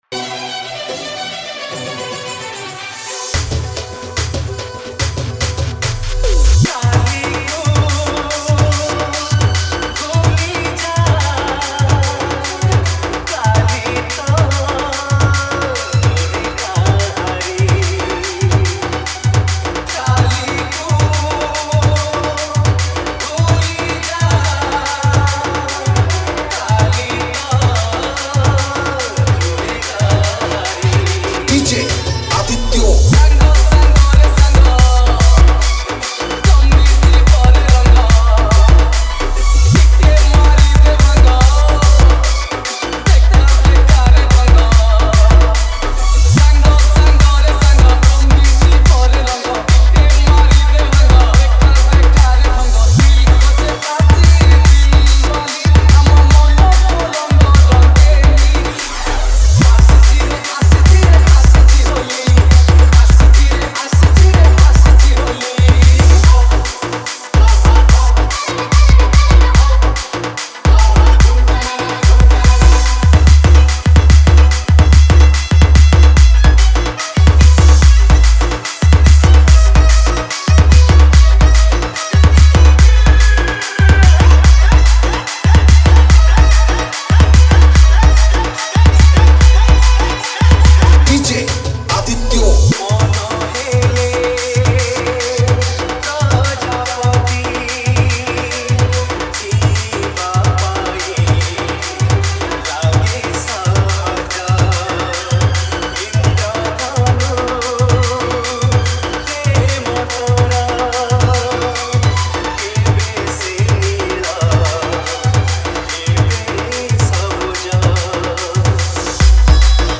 HOLI SPECIAL DJ SONG